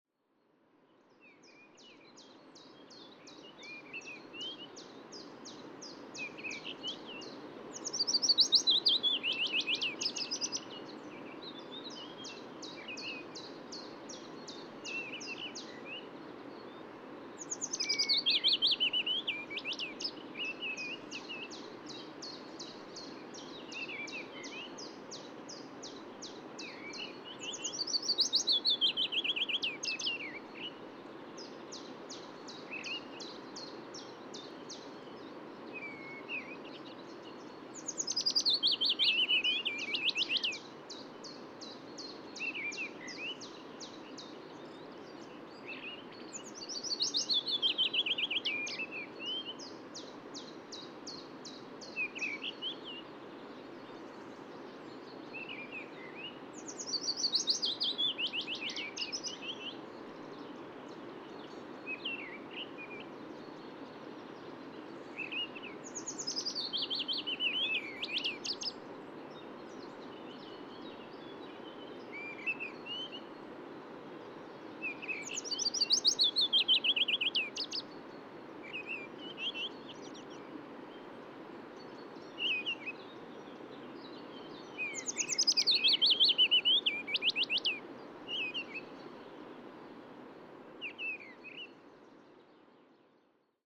Krkonoše National Park
Common Chaffinch Fringilla c. coelebs, adult male song